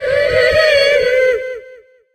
tara_lead_vo_02.ogg